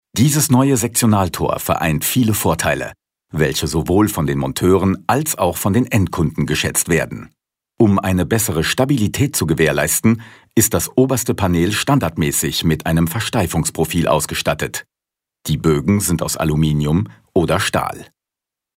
Dank seiner angenehmen Tonlage, nicht zu tief und auch nicht zu hell, ist er sehr flexibel einsetzbar.